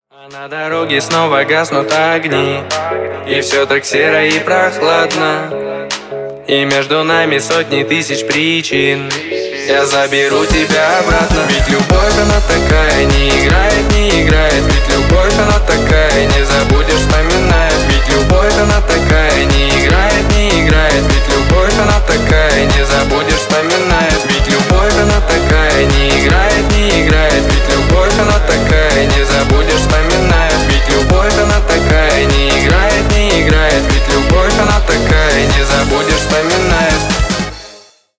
• Качество: 320 kbps, Stereo
Поп Музыка
весёлые